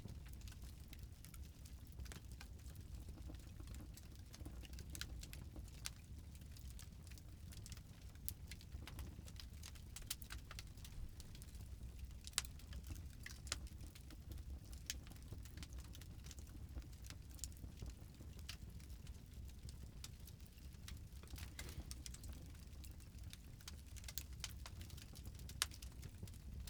campfireloop.ogg